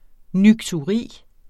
Udtale [ nyktuˈʁiˀ ]